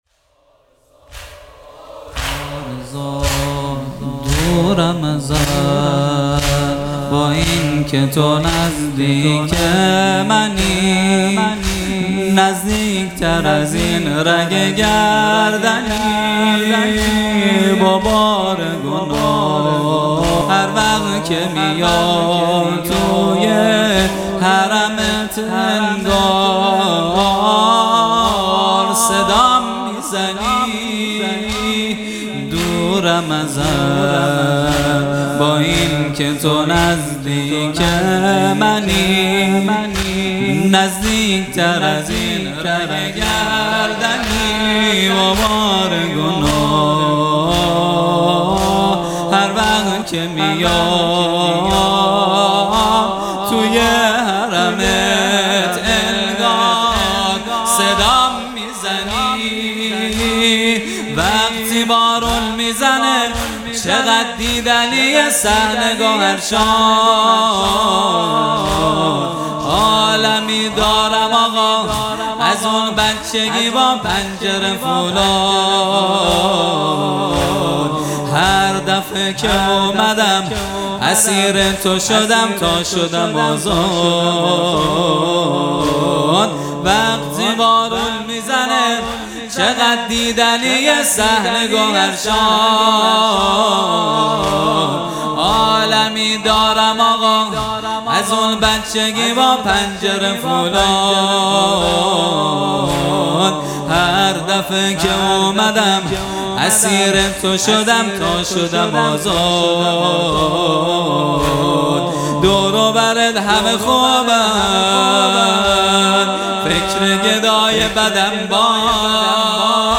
زمینه | دورم ازت با اینکه تو نزدیک منی | 2 تیر 1401
جلسۀ هفتگی | به مناسبت شهادت امام رضا(علیه السّلام) | پنجشنبه 2 تیر 1401